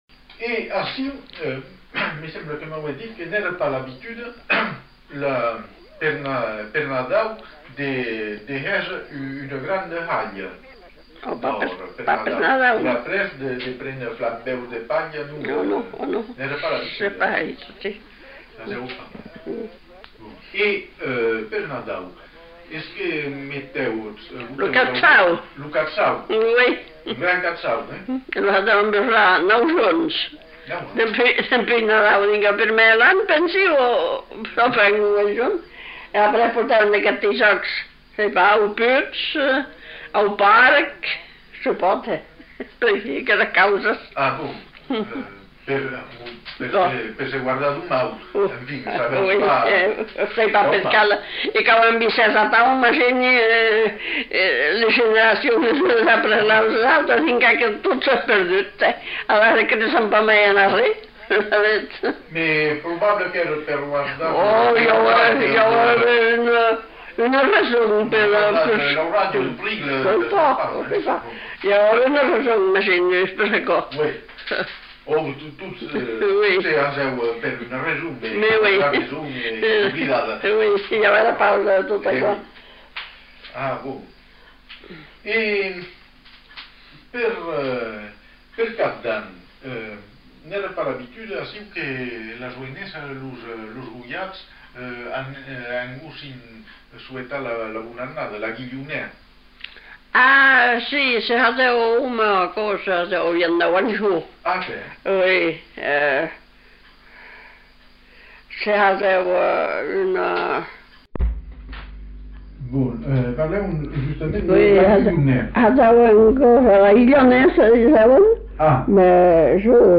Lieu : Sauméjan
Genre : témoignage thématique